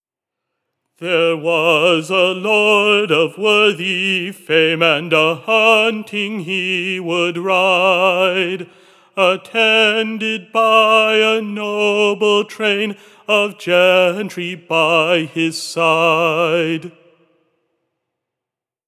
38_isabella_31937_st1_ladysfall_major.mp3 (592.53 KB)